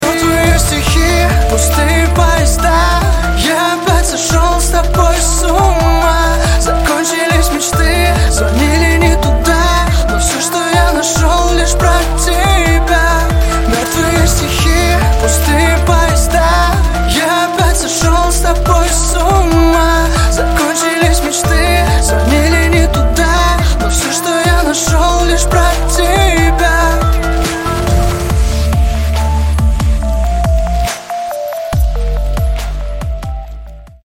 лирика
Хип-хоп
мелодичные